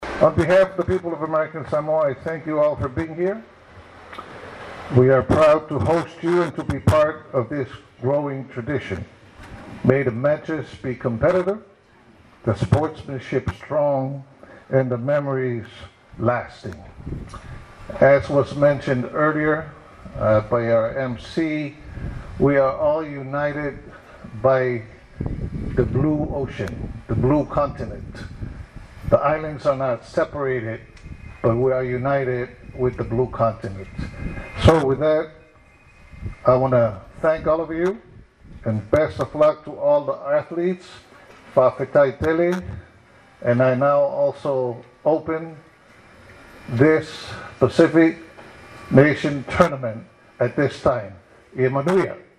Governor Pulaalii Nikolao Pula delivered the first serve to open the Pacific Nations Cup at the tennis courts in Tafuna Monday morning.
Governor Pulaalii said the Pacific Nations Cup is not just about winning medals, it’s about unity.